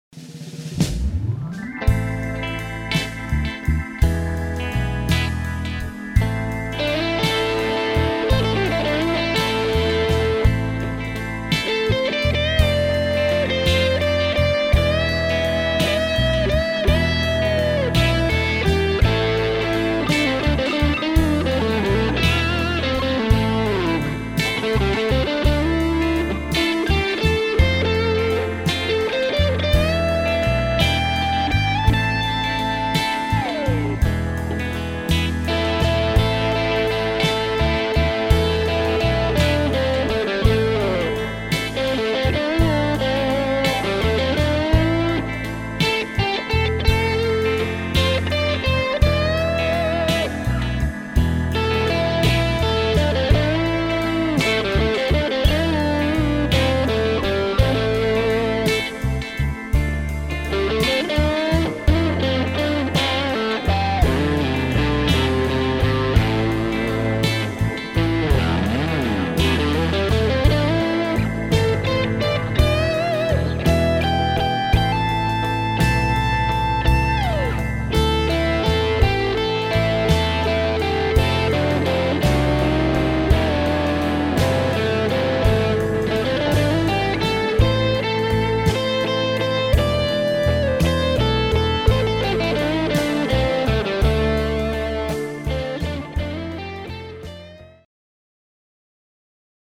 lesson sample
level 2  Full Demo & Backing Tracks  CD/Tape   Download